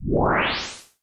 whoosh2.ogg